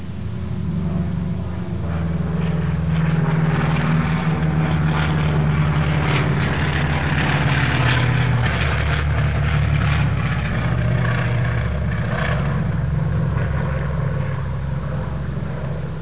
دانلود آهنگ طیاره 25 از افکت صوتی حمل و نقل
دانلود صدای طیاره 25 از ساعد نیوز با لینک مستقیم و کیفیت بالا
جلوه های صوتی